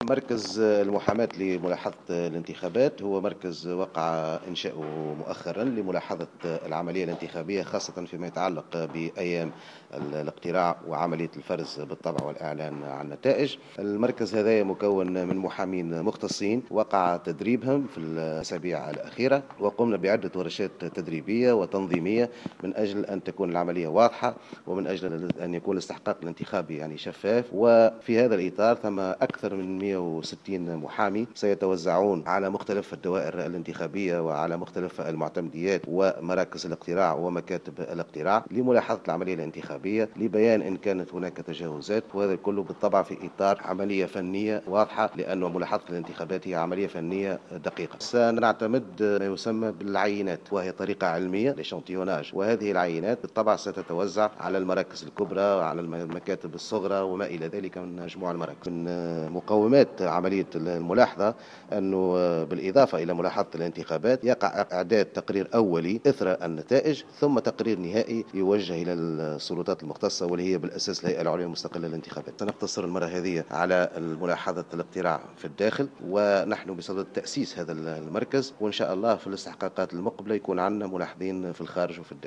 أعلن عميد المحامين الفاضل محفوظ خلال ندوة صحفية عقدت بالعاصمة اليوم الجمعة 24 أكتوبر 2014 عن احداث هيكل جديد لملاحظة الإنتخابات وهو مركز المحاماة خلال أيام الإقتراع والفرز الى اعلان النتائج وهو مكون من محامين مختصين وقع تدريبهم في الأسابيع الأخيرة مؤكدا أن المركز قام بعديد الورشات التدريبية والتنظيمية لتكون عملية الاقتراع شفافة على حد قوله.